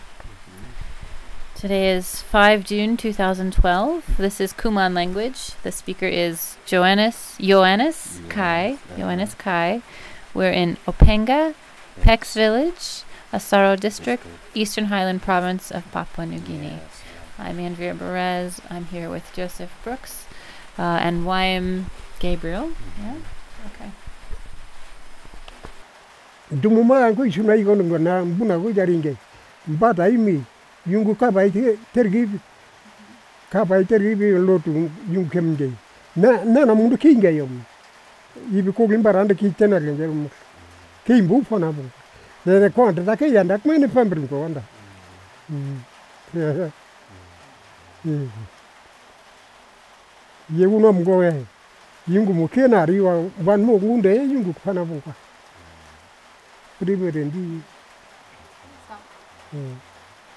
digital wav file recorded at 44.1 kHz/16 bit on Zoom H4n solid state recorder with Countryman e6 headset microphone
Openga, Eastern Highlands Province, Papua New Guinea